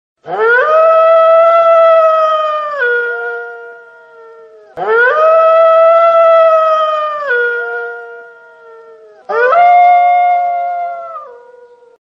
Категория: Живые звуки, имитация